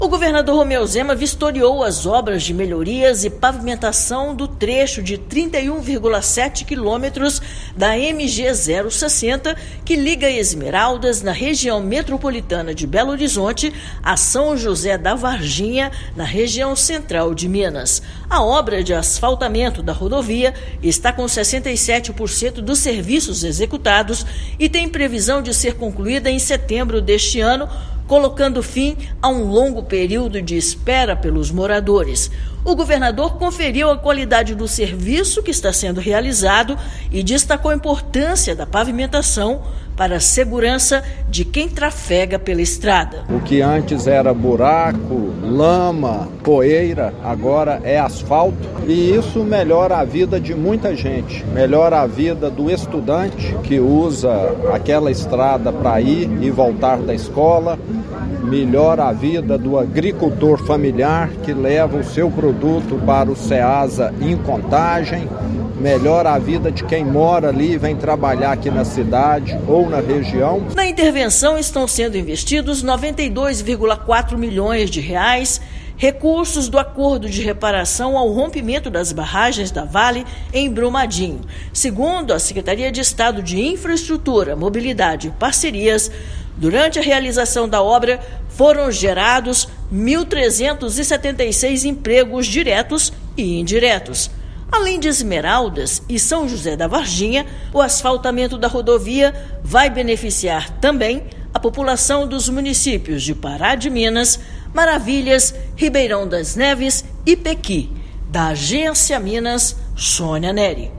Trecho de 31,7 quilômetros, que liga a cidade da Região Metropolitana de Belo Horizonte (RMBH) à região Central do estado será asfaltado após mais de 30 anos de espera. Ouça matéria de rádio.